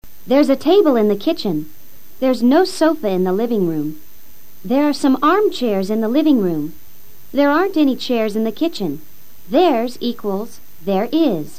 Escucha a la profesora leyendo oraciones con esta NUEVA GRAMATICA.